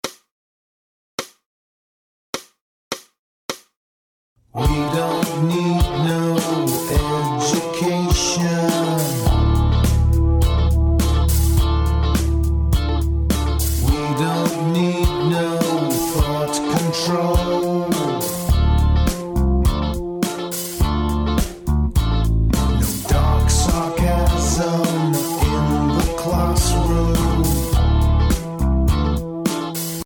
Voicing: Guitar Tab